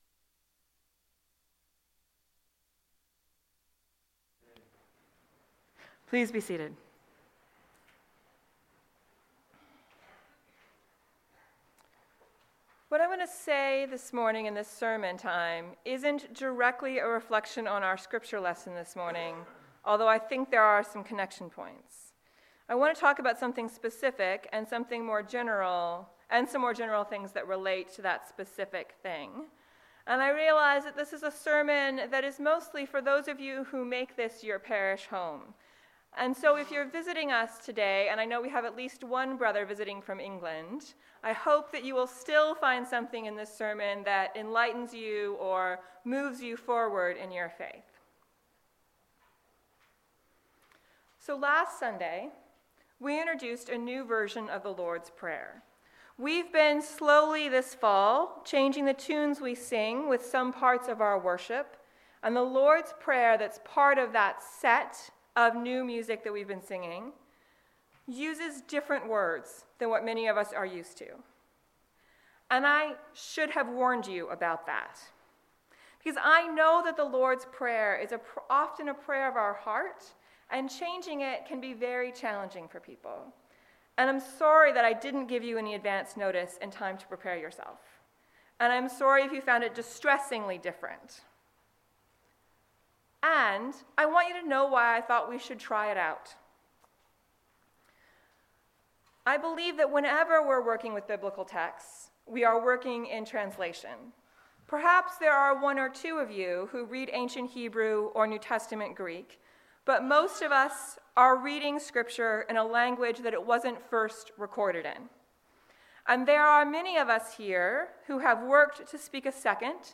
This sermon addressed an issue the Parish faced using a new setting to sing the Lord's Prayer. It both speaks about how we understand Scripture and how we handle disagreements in our Parish.
Download Download Reference 19th Sunday after Pentecost.